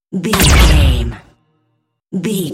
Dramatic stab laser shot deep
Sound Effects
Atonal
heavy
intense
dark
aggressive